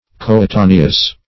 Coetaneous \Co`e*ta"ne*ous\, a. [L. coaetaneus; co- + aetas